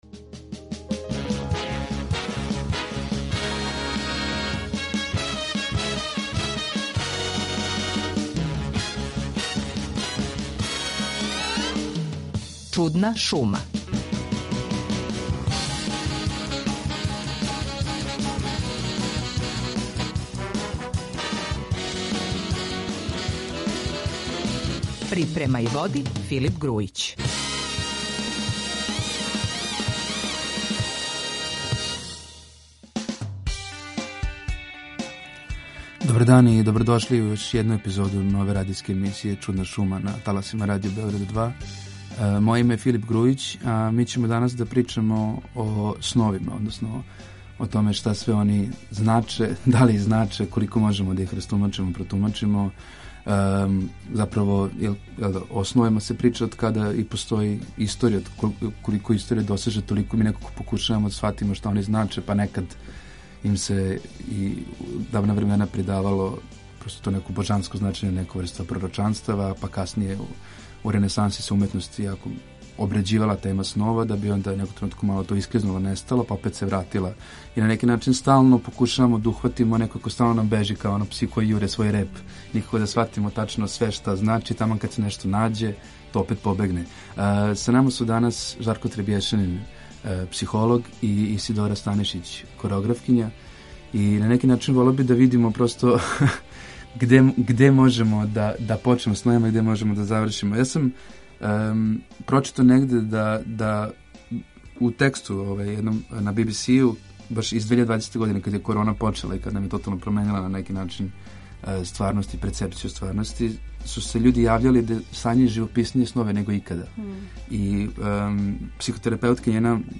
У новој епизоди емисије Чудна шума разговараћемо на тему снова